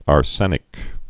(är-sĕnĭk)